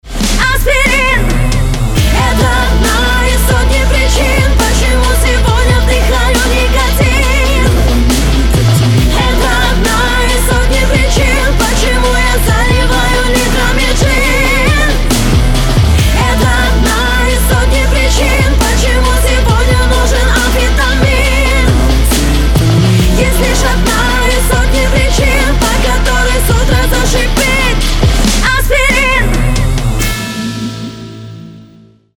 • Качество: 320, Stereo
громкие
женский вокал
Rap-rock
русский рэп
дуэт
энергичные